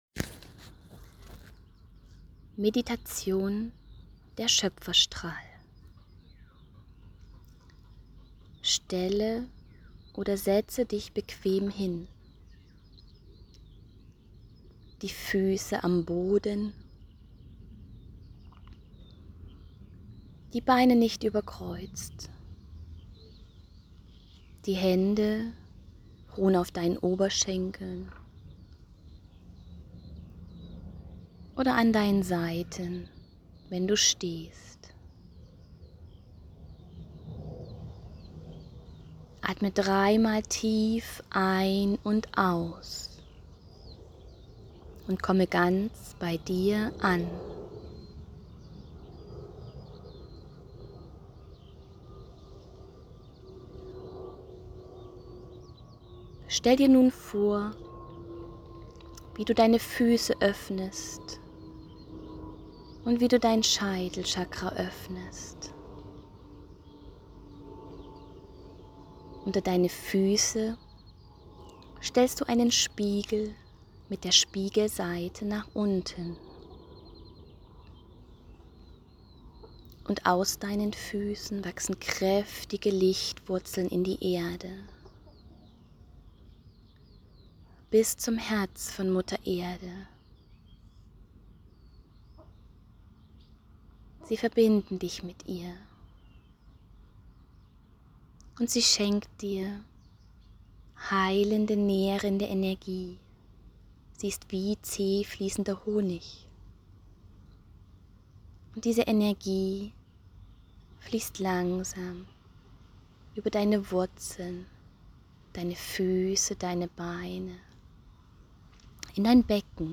Diese Meditation ist ein solcher Ort.